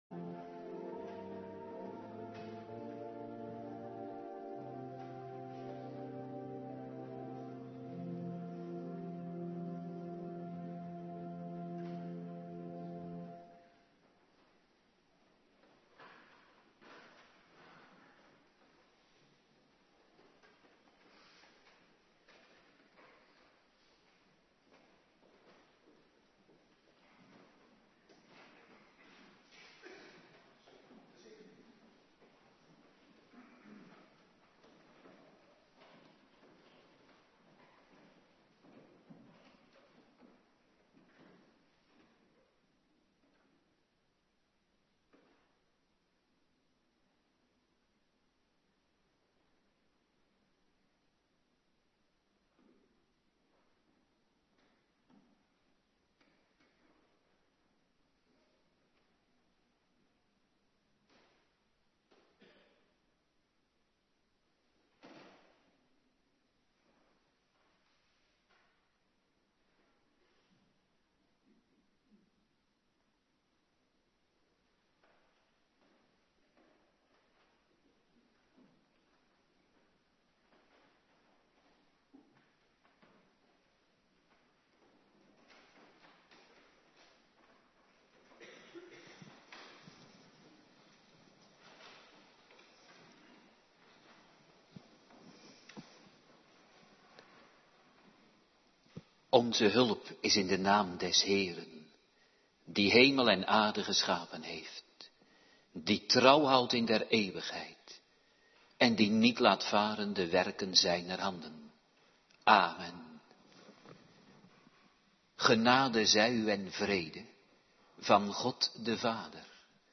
Woensdagavonddienst
19:30 t/m 21:00 Locatie: Hervormde Gemeente Waarder Agenda
Genesis 9:18-28 Terugluisteren Bijbellezing Genesis 9:18-28